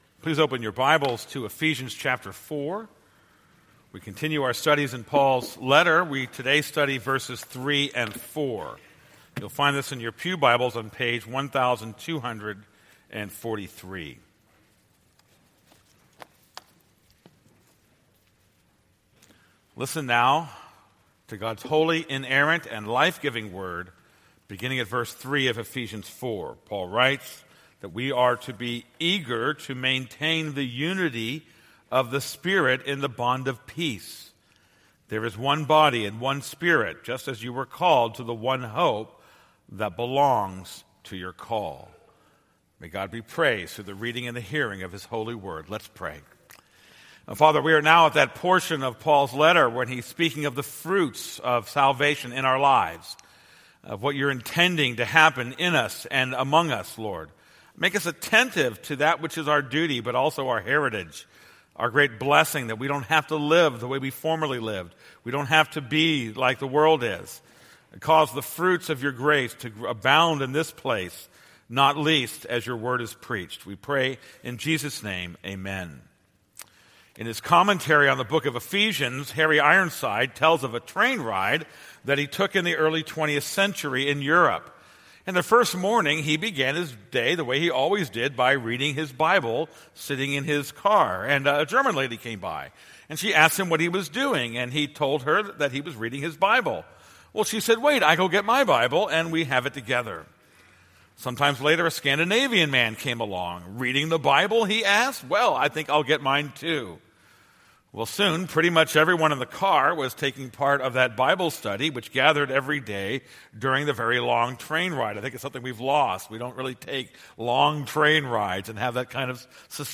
This is a sermon on Ephesians 4:3-4.